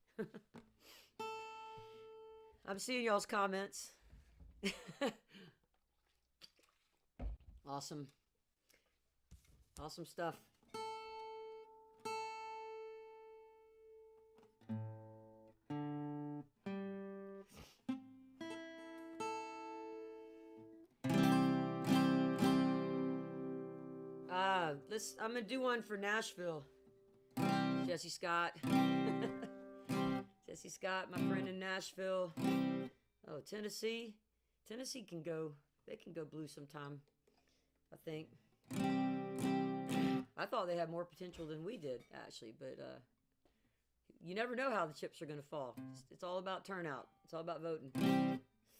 (audio from a portion of the program captured from webcast)
10. talking with the crowd (amy ray) (0:48)